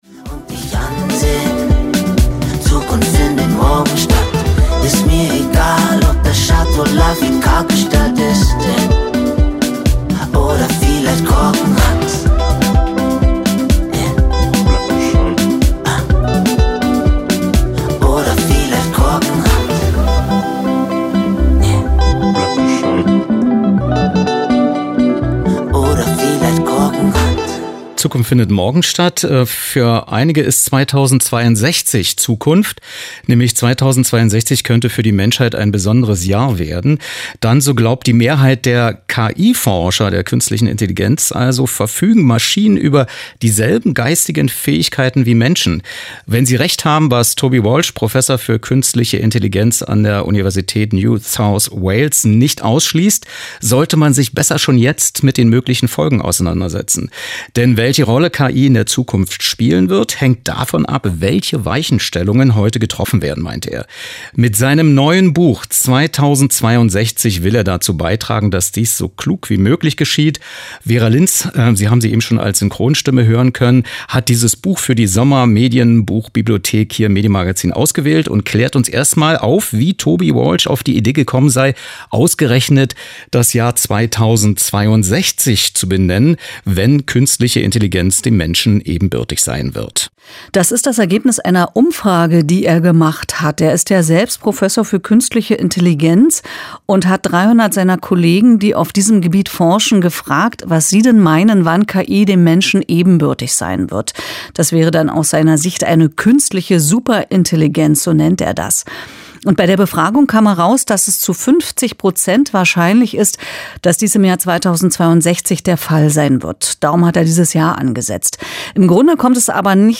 Was: Studiogespräch zum Buch
Wo: Senderegie radioeins, Potsdam-Babelsberg, Medienstadt